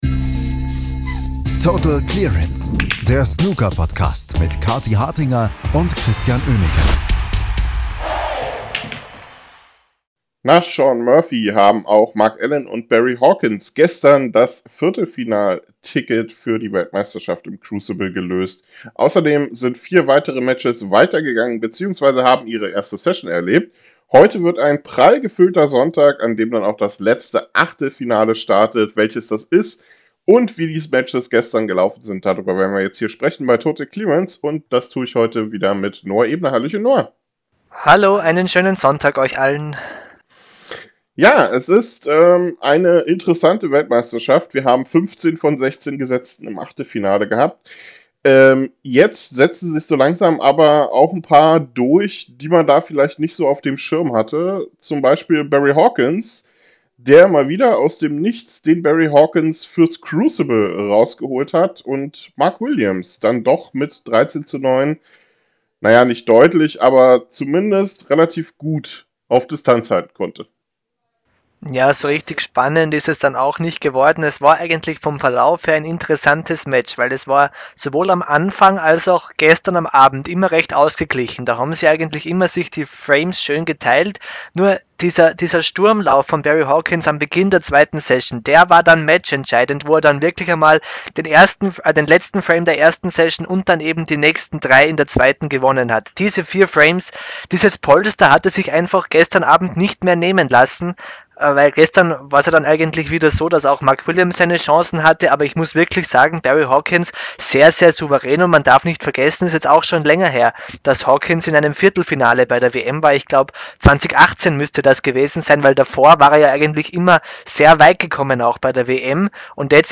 Mark Allen brauchte einen starken Schlussspurt bei der Snooker-WM in Sheffield, um wie Mark Williams in die 2. Runde einzuziehen. Interview mit Kyren Wilson